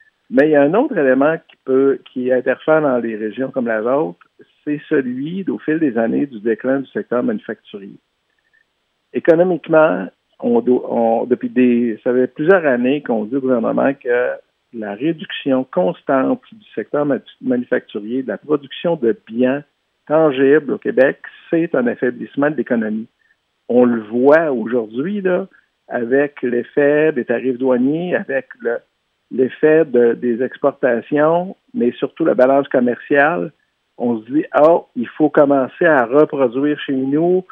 En entrevue avec le service de nouvelles de M105